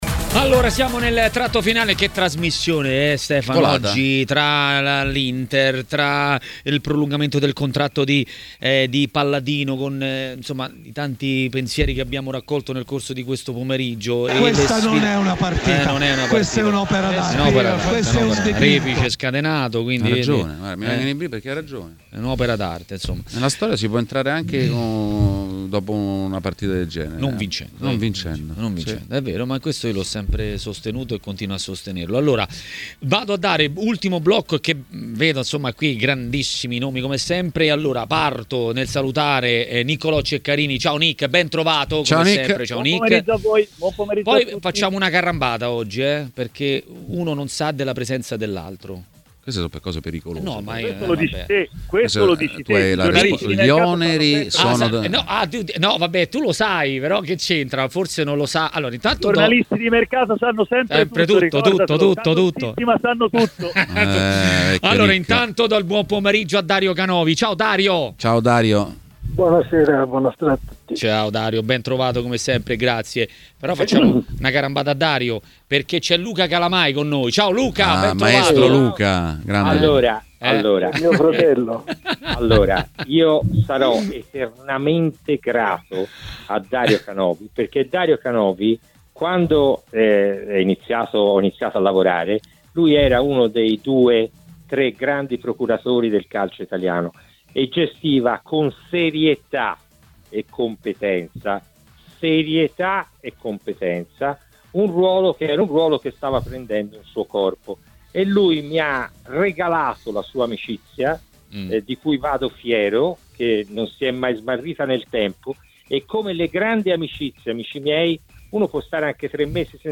ha parlato ai microfoni di TMW Radio, durante Maracanà.